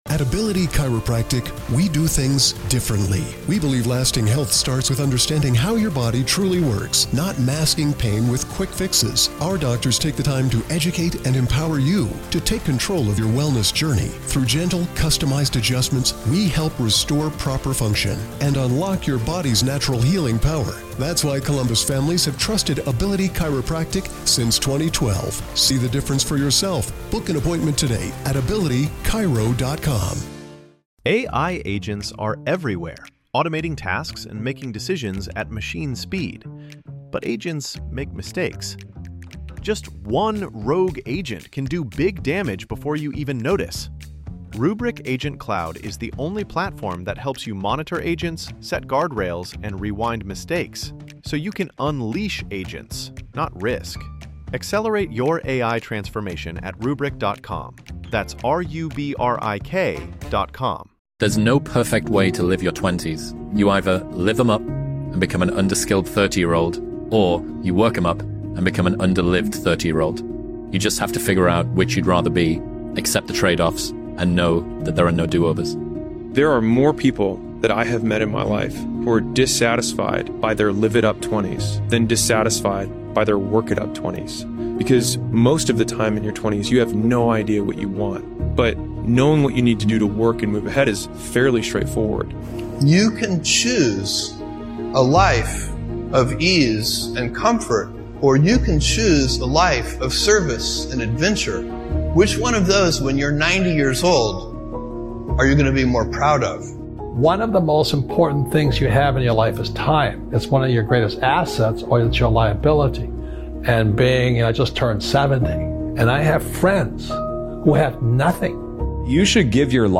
Speakers: Alex Hormozi Codie Sanchez Dan Martell Dan Pena Kim Kiyosaki Robert Kiyosaki Tom Bilyeu Wallstreet Trapper Zig Ziglar